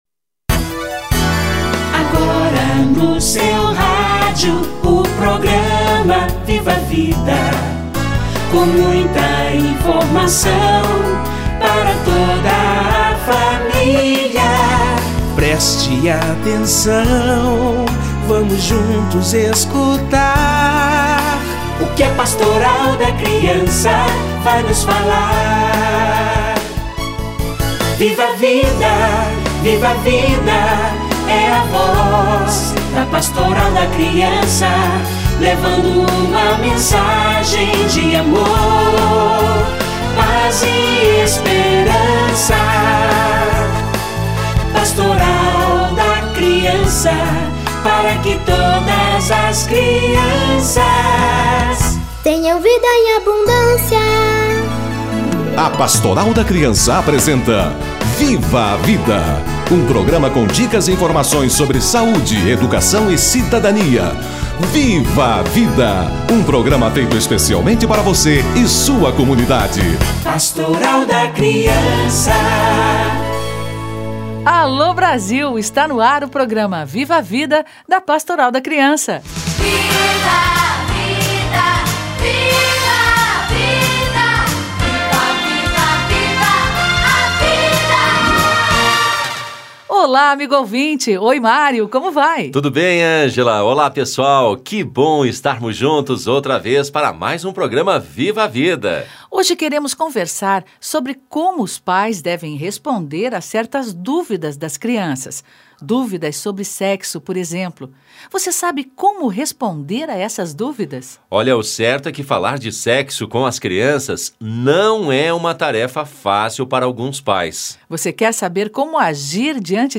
Sexualidade: como responder as dúvidas das crianças - Entrevista